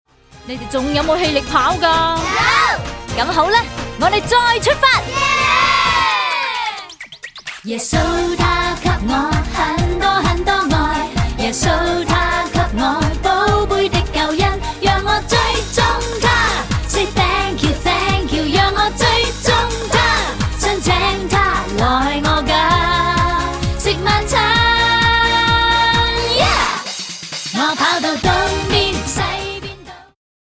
充滿動感和時代感